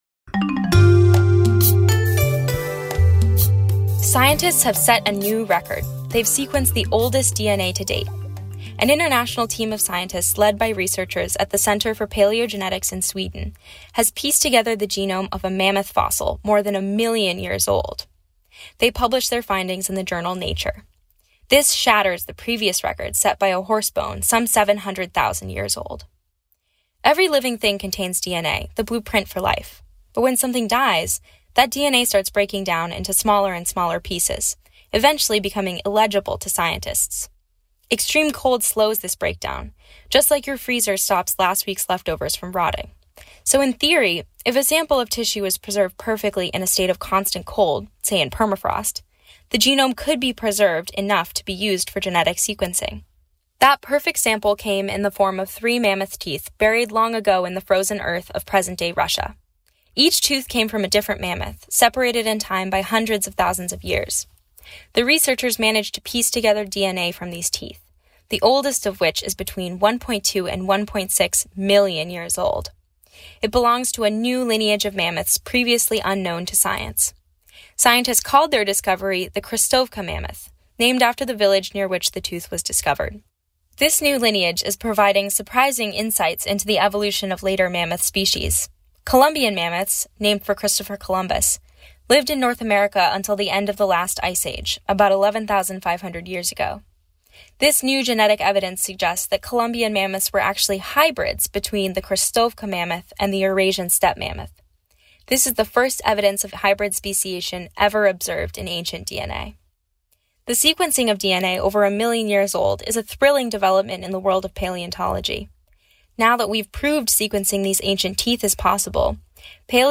Public Radio's Environmental News Magazine (follow us on Google News)